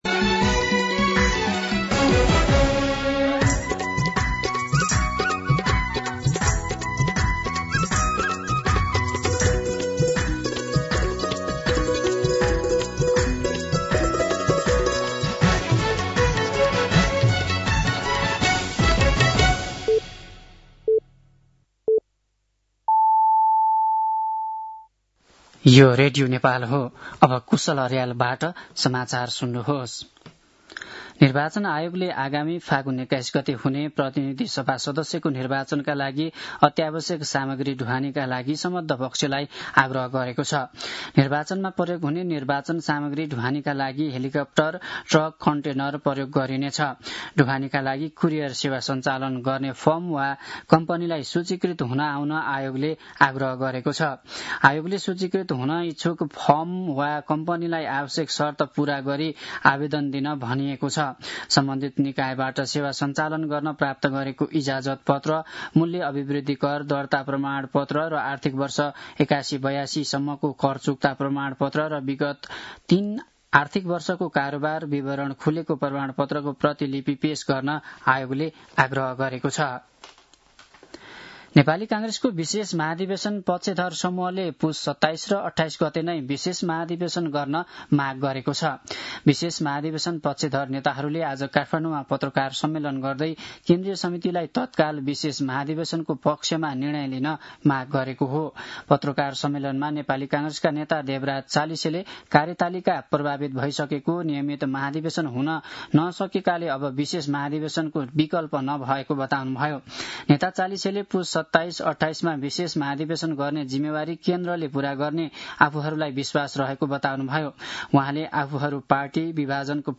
दिउँसो ४ बजेको नेपाली समाचार : १८ पुष , २०८२
4-pm-News-9-18.mp3